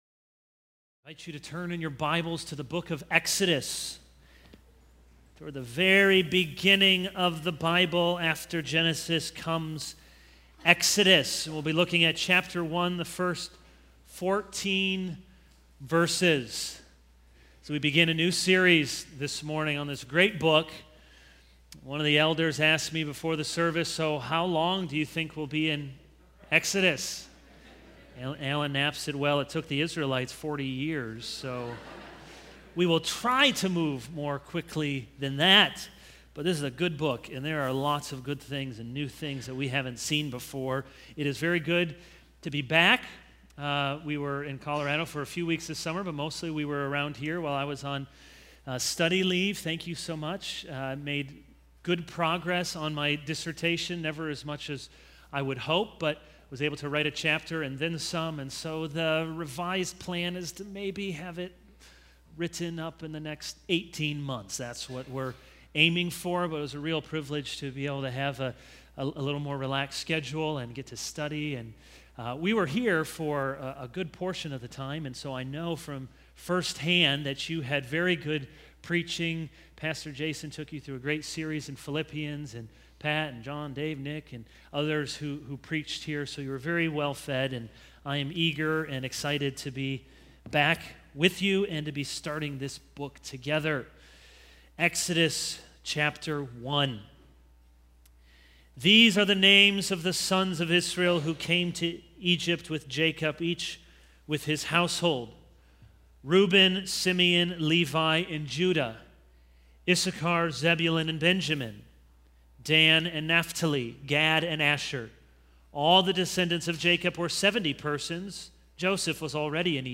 This is a sermon on Exodus 1:1-14.